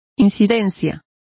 Complete with pronunciation of the translation of incidence.